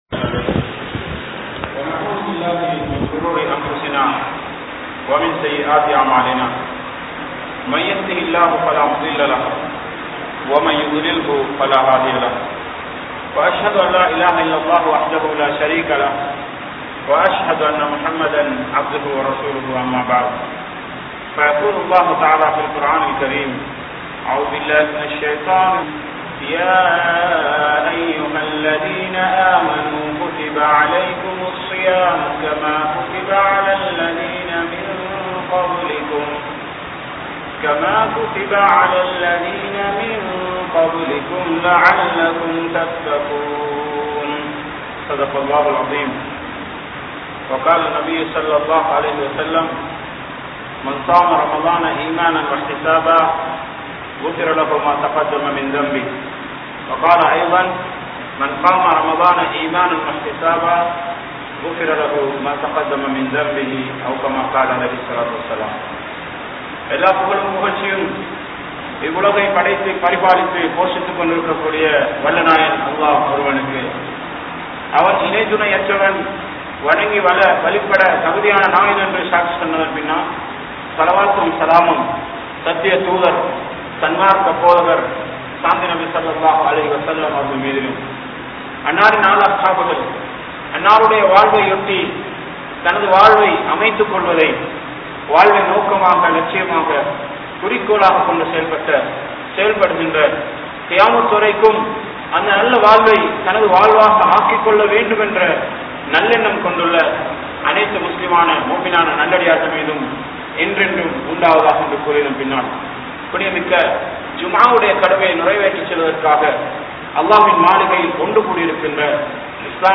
Ramalaanin Sirappuhal | Audio Bayans | All Ceylon Muslim Youth Community | Addalaichenai